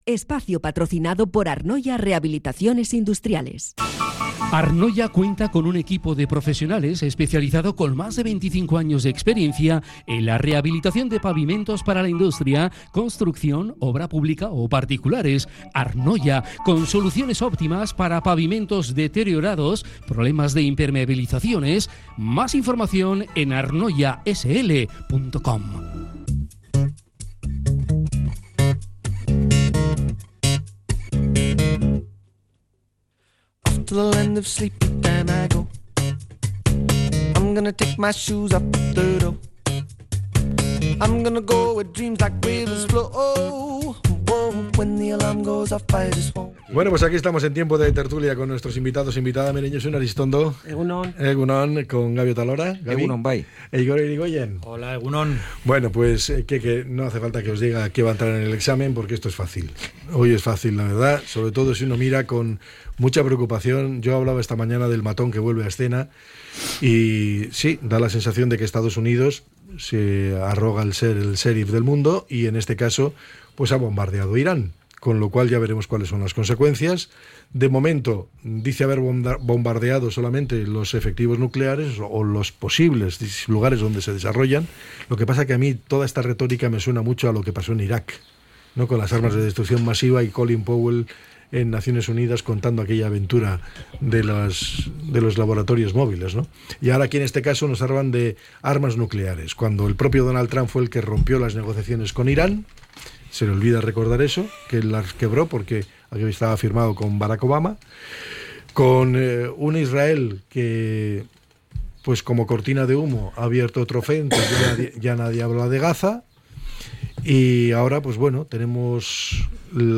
La tertulia 23-06-25.